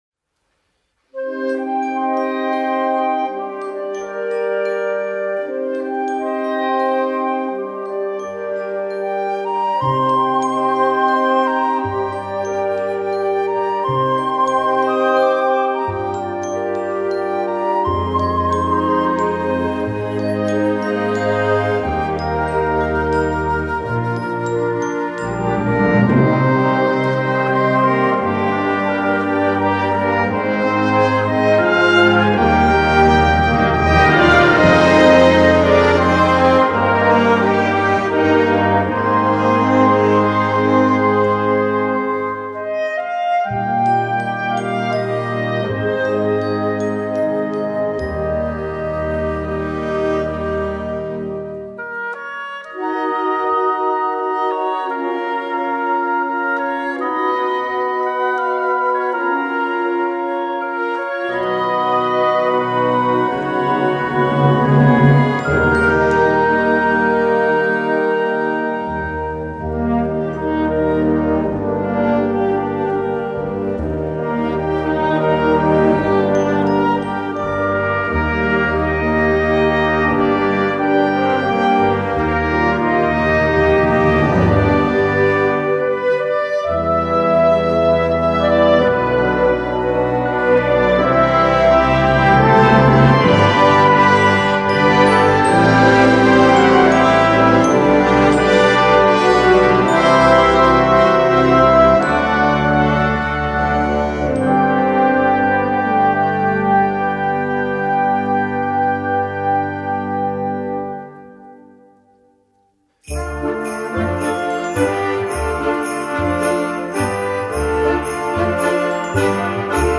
Sheet music from the movies for Brass Band